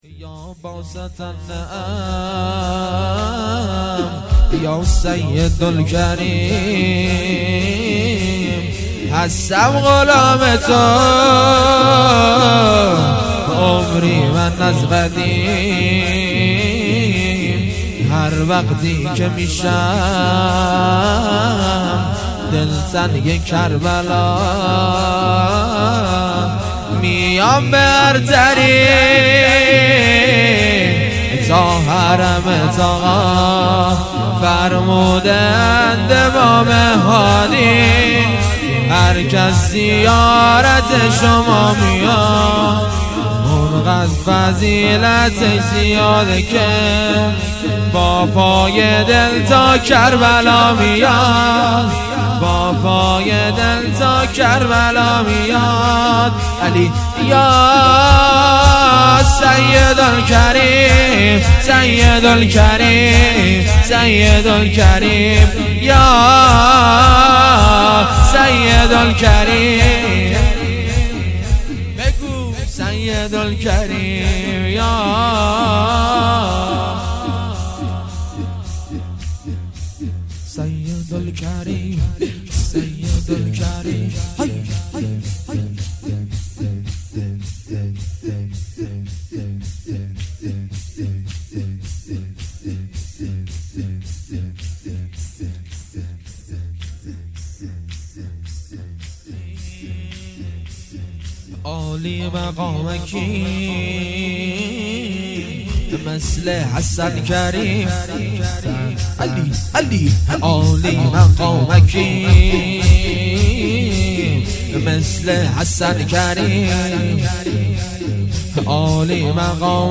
زمینه حضرت عبدالعظیم،یا باسط النعم